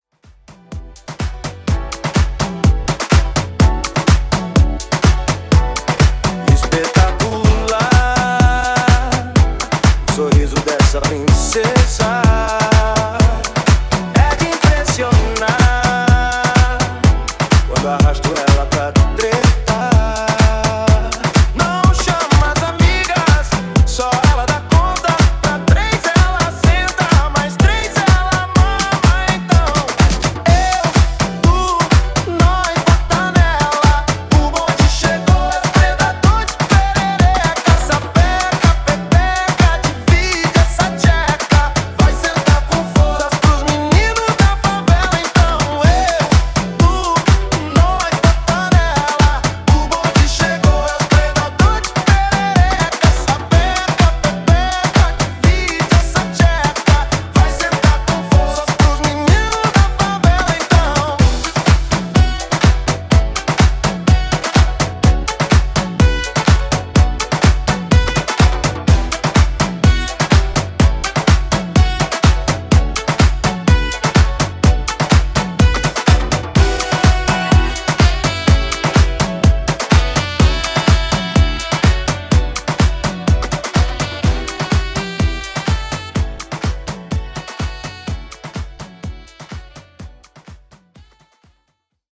Tech House)Date Added